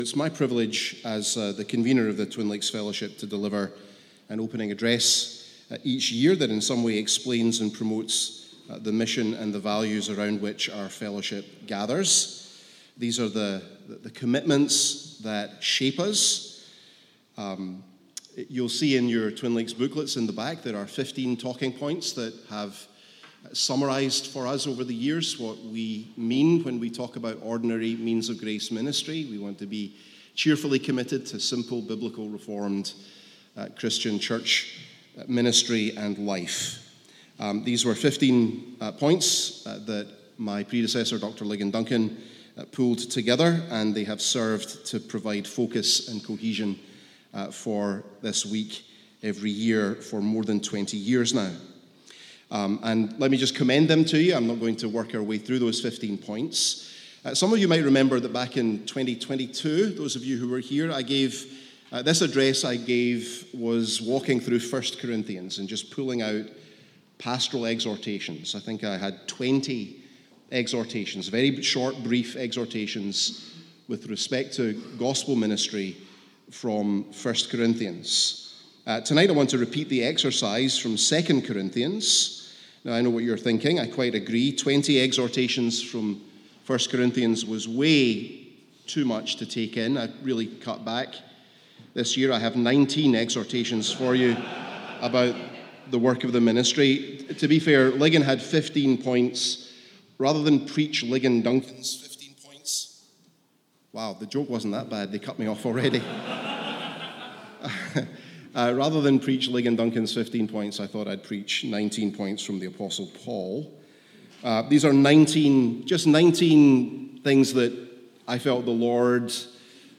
Welcome Address: Exhortations from 2 Corinthians